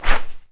SWISH 3   -S.WAV